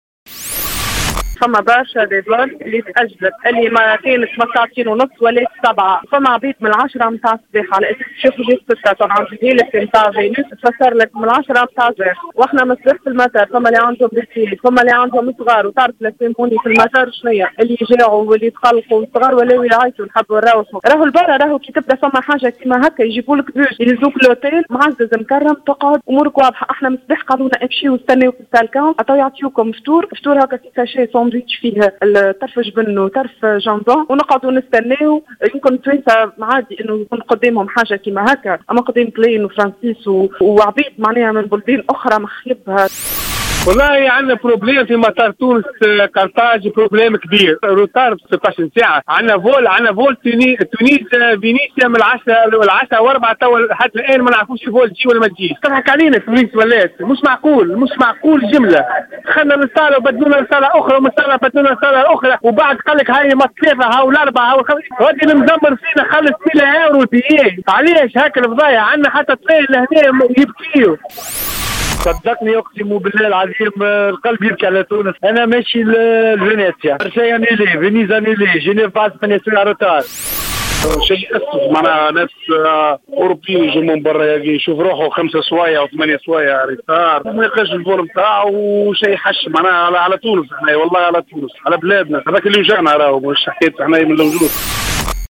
هذا وعبر عدد من المسافرين في اتصال هاتفي للجوهرة أف-أم عن تذمرهم من خدمات الناقلة الوطنية.